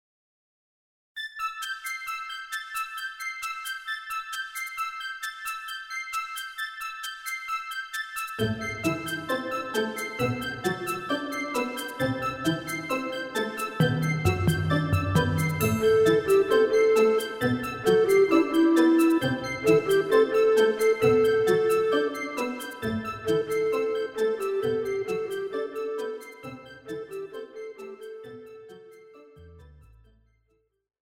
KARAOKE/FORMÁT:
Žánr: Pop